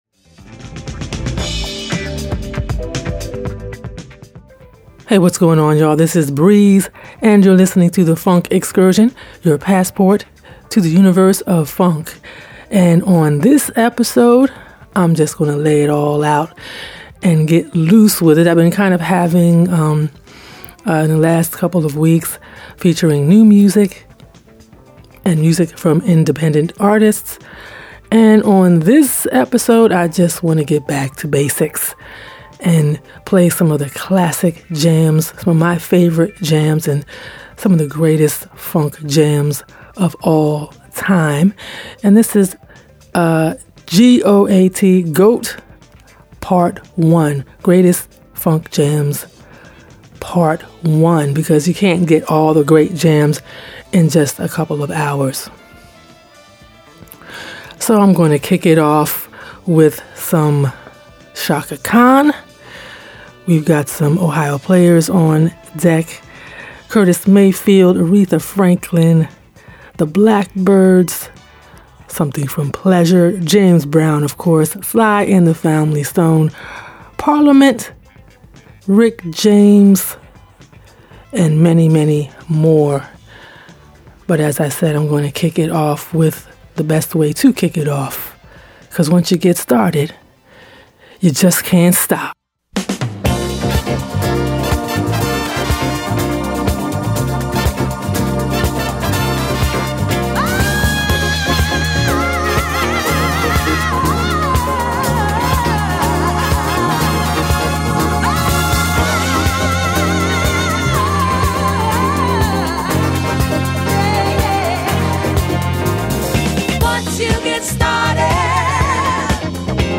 funky mix of mother’s day standards